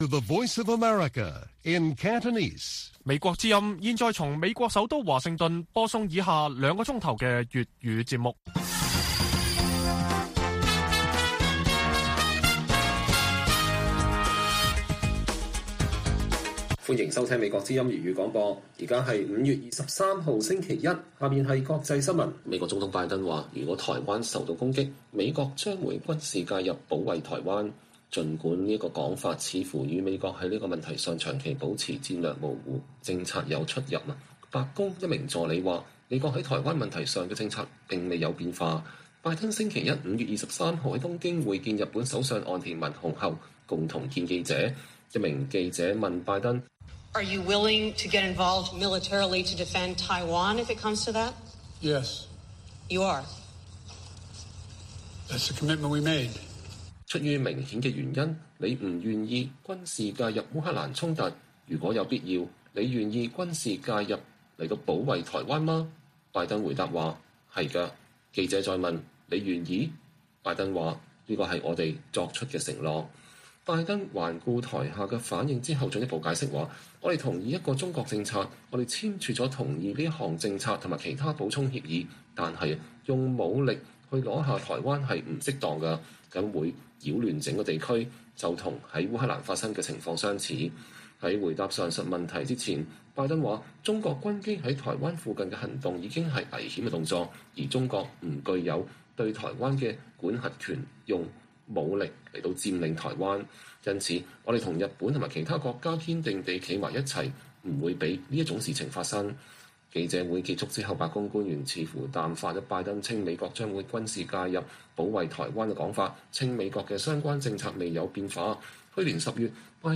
粵語新聞 晚上9-10點: 拜登稱若台灣被攻擊美國將軍事介入